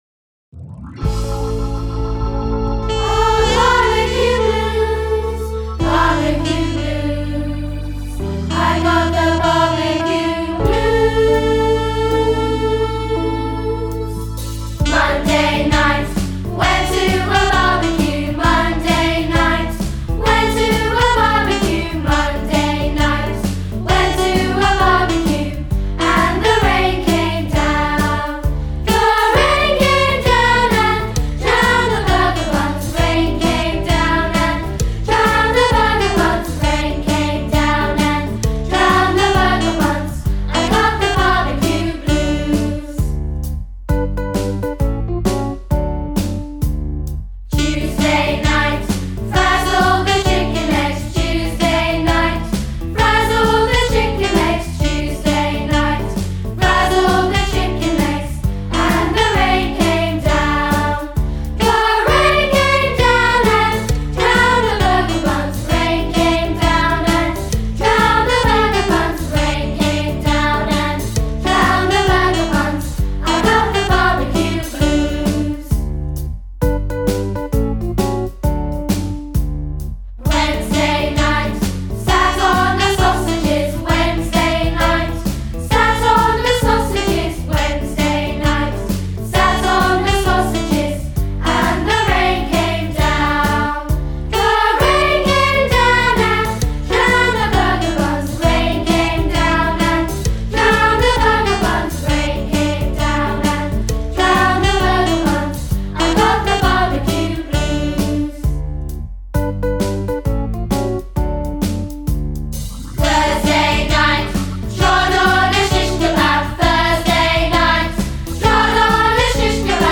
(just instruments) or the choir, please click on the